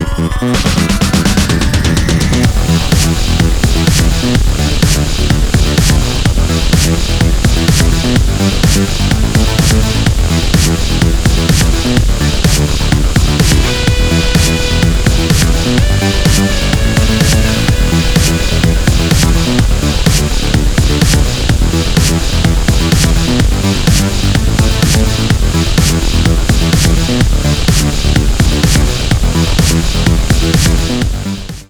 • Качество: 320, Stereo
громкие
зажигательные
мощные басы
без слов
OST
techno